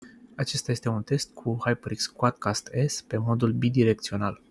Sunet clar pentru toate modurile alese
• Bidirectional